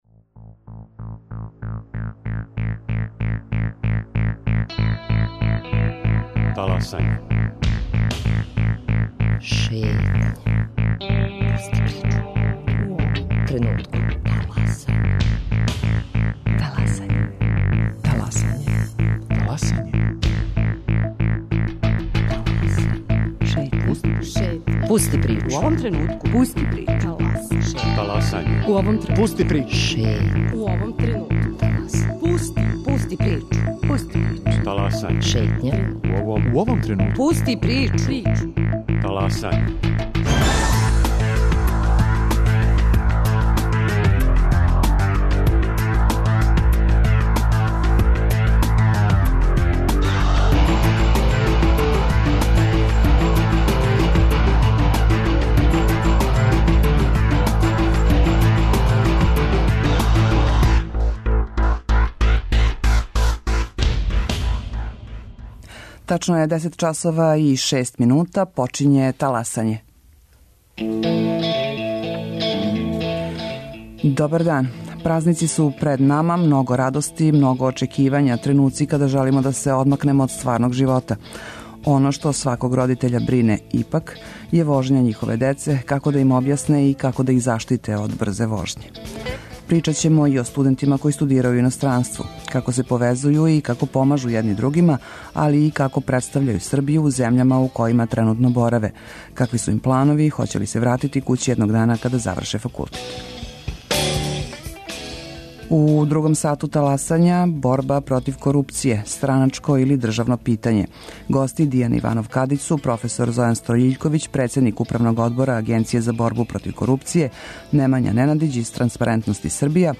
Прилика да разговарамо са представницима Организације српских студената у иностранству о томе како се повезује, како студирају, шта планирају.